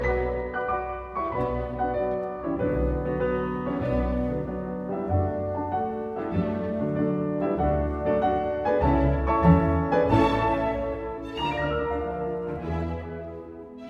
Il est suivi du rythme de l'Idée secondaire aux cordes sur des arpèges du piano (mes. 199, env. 9'41''), passe dans la tonalité de Ré Majeur toujours sur le rythme de l'Idée secondaire aux cordes et cela se prolonge ; à la mes. 215 env. 10'22'', toujours sur le même rythme, le piano commente l'idée par des accords en rythmes pointés.